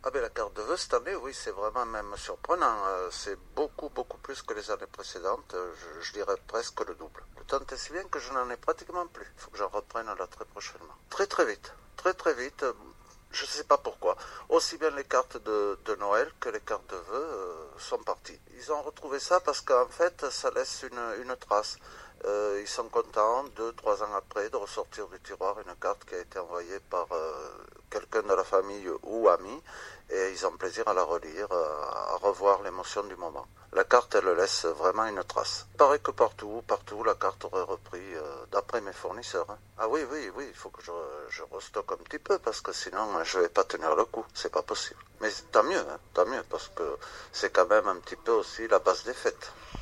On entend le son « é » dans le sud de la France.